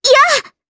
Kirze-Vox_Attack3_jp.wav